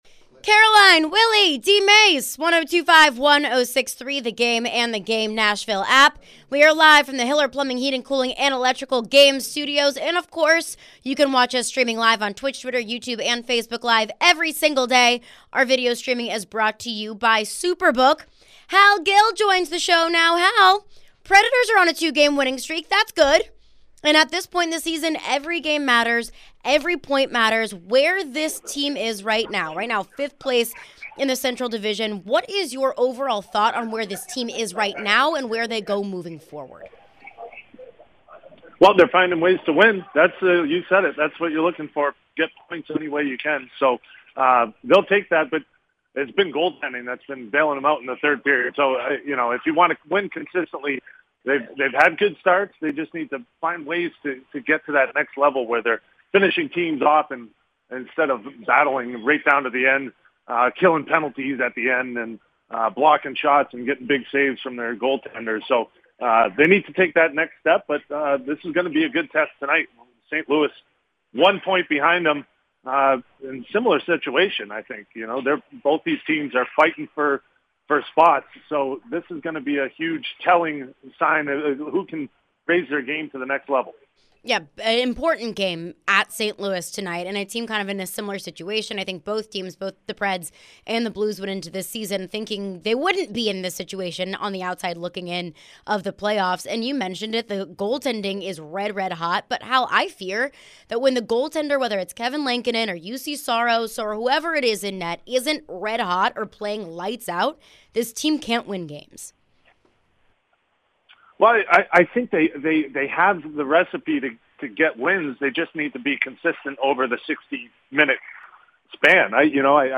Hal Gill Interview (1-19-23)